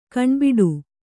♪ kaṇbiḍu